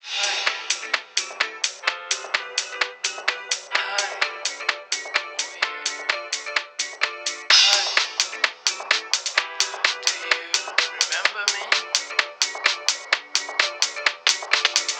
fill filtré.wav